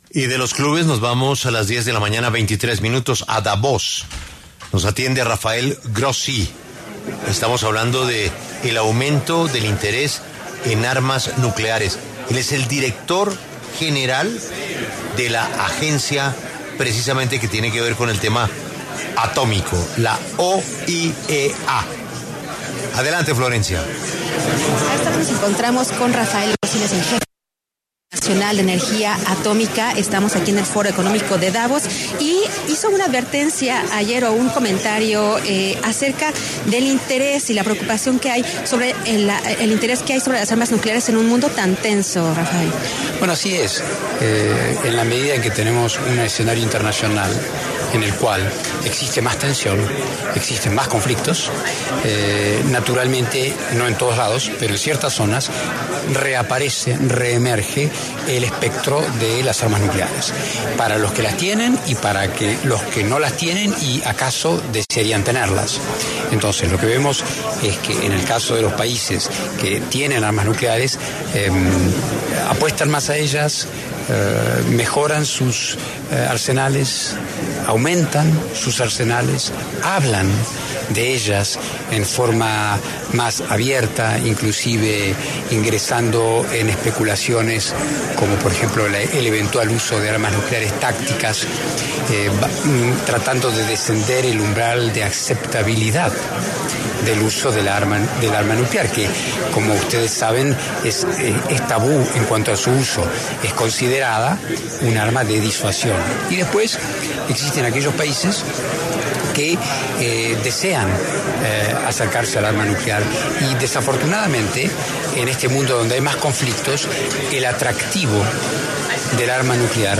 Desde el Foro Económico Mundial de Davos, en Suiza, el director general del Organismo Internacional de Energía Atómica, OIEA, Rafael Mariano Grossi, pasó por los micrófonos de La W para hablar sobre la preocupación que hay por el uso de armas nucleares en un mundo tan tenso.